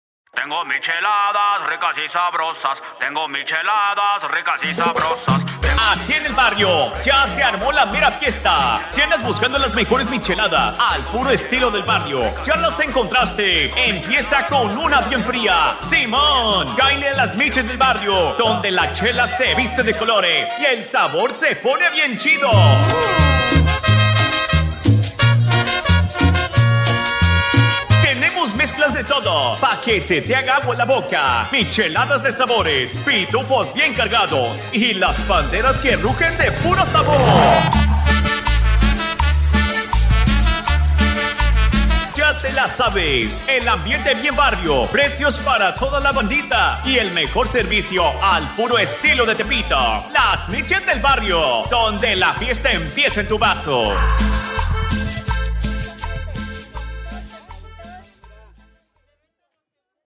SPOT PUBLICITARIO PARA VENTA DE